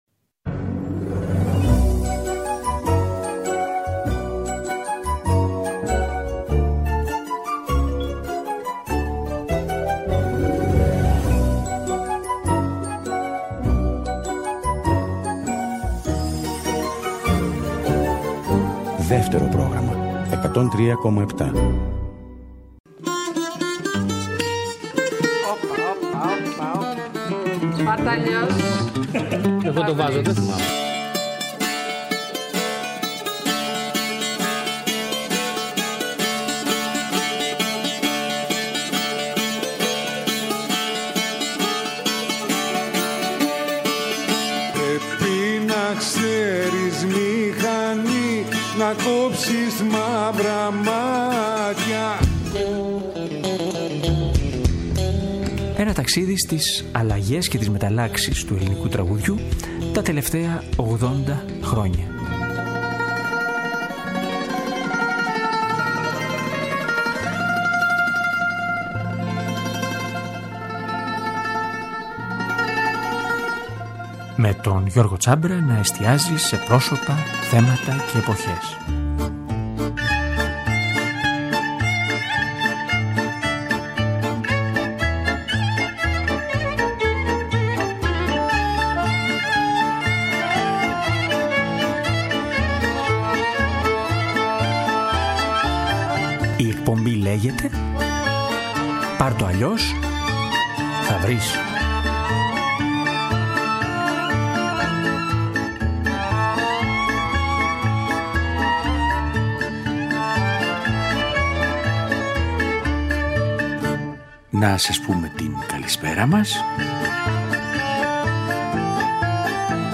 μπουζούκι
πιάνο